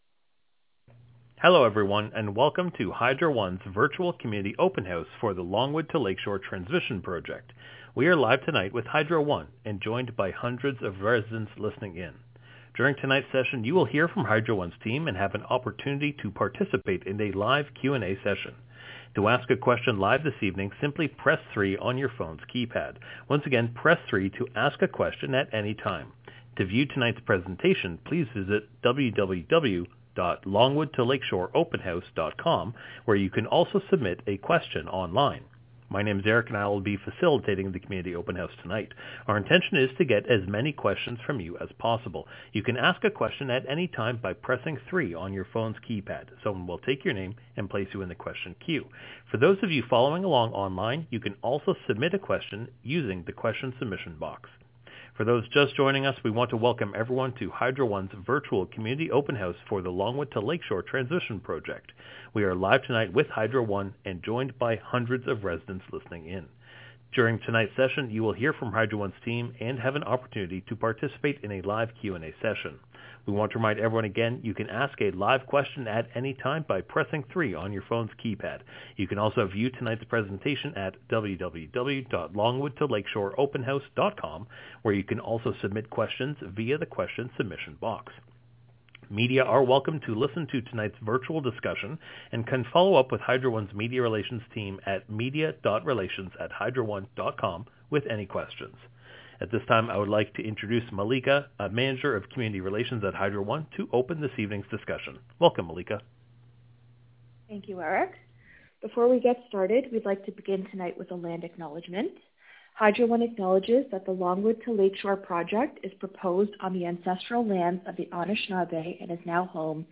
April 2024 Virtual Community Open House Audio Recording (.WAV - 59MB)
Virtual-Community-Open-House-Event-Recording-April-2024.wav